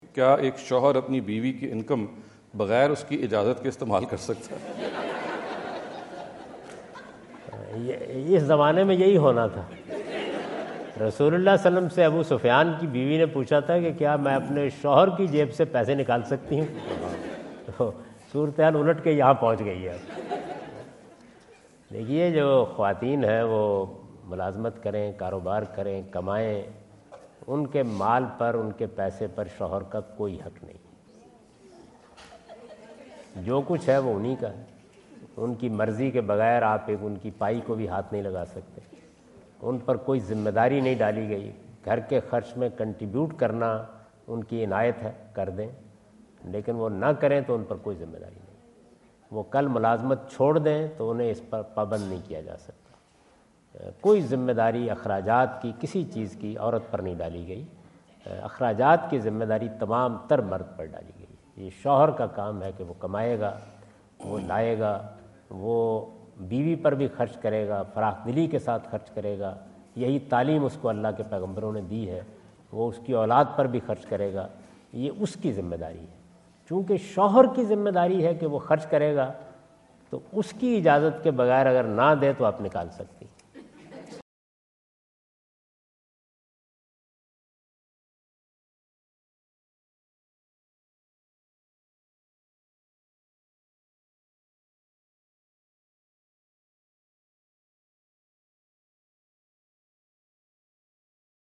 In this video Javed Ahmad Ghamidi answer the question about "can a husband use wife’s money without permission?" asked at The University of Houston, Houston Texas on November 05,2017.
جاوید احمد صاحب غامدی دورہ امریکہ2017 کے دوران ہیوسٹن ٹیکساس میں "بیوی کی کمائی اس کی اجازت کے بغیر استعمال کرنا؟" سے متعلق ایک سوال کا جواب دے رہے ہیں۔